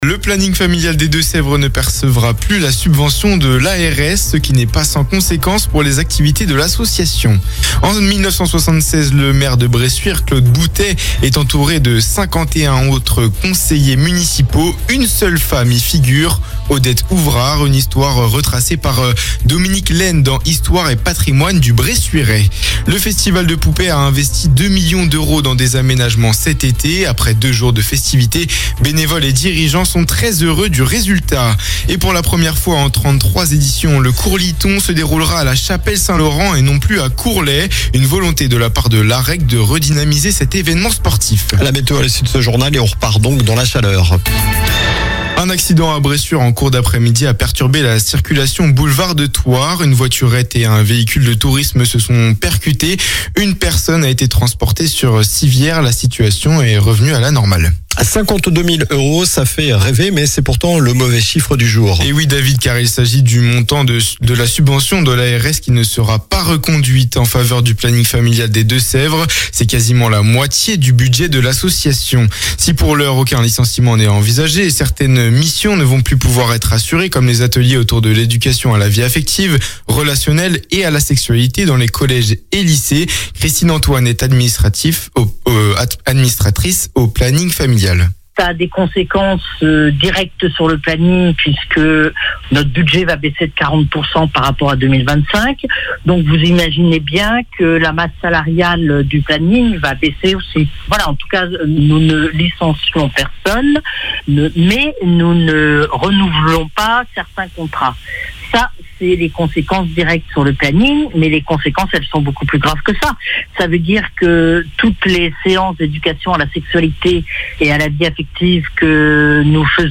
Journal du vendredi 27 juin (soir)